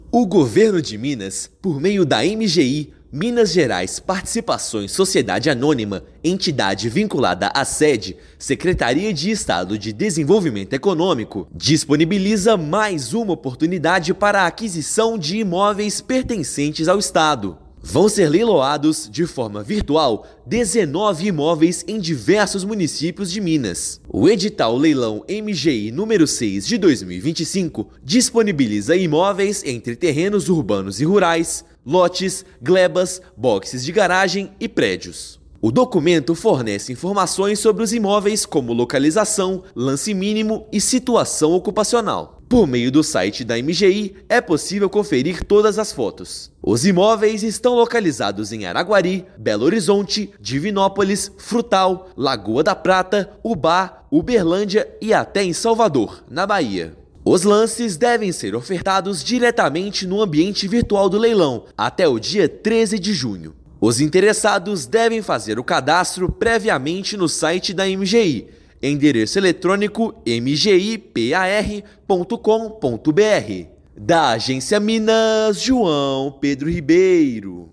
Entre os 19 imóveis disponíveis estão terrenos urbanos e rurais, lotes, glebas, boxes de garagem e prédios. Ouça matéria de rádio.